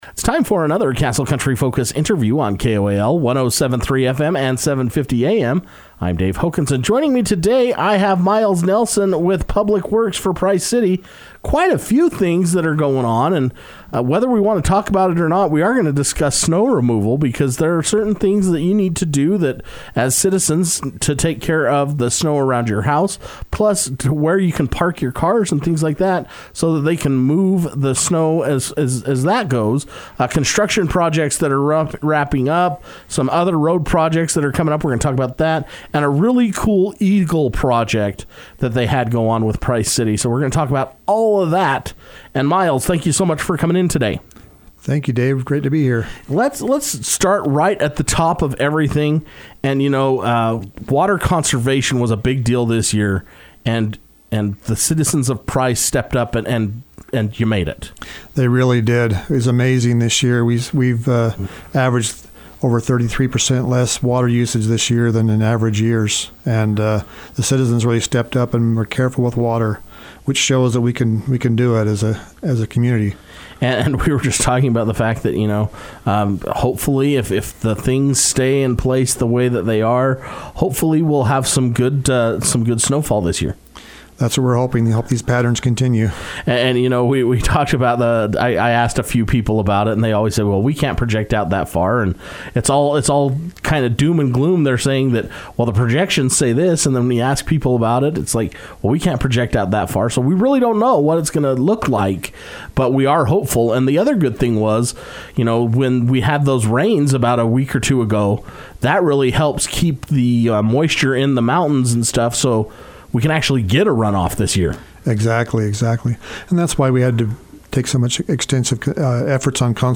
Price City each month sends a representative to Castle Country Radio to keep listeners posted or informed on various topics. This month brought in Price City Public Works Director, Miles Nelson who spoke on water conservation, leaf and snow removal, and road projects.